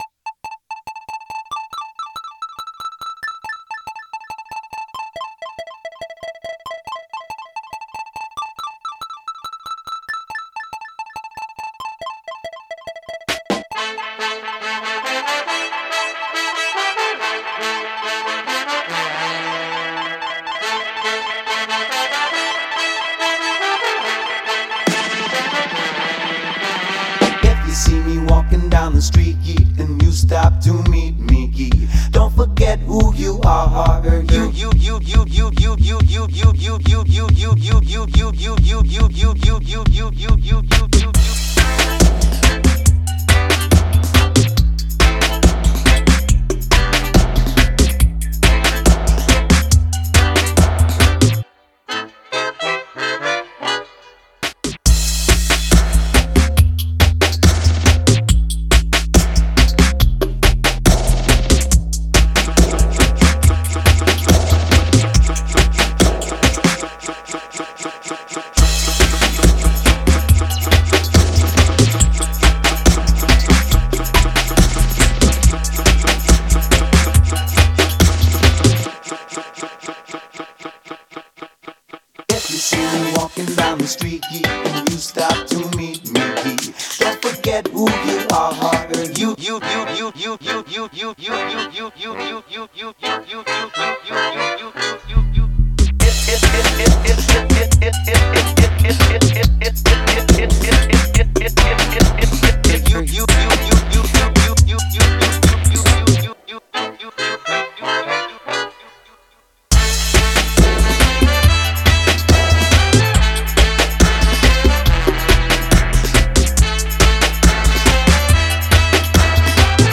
Genre: Telugu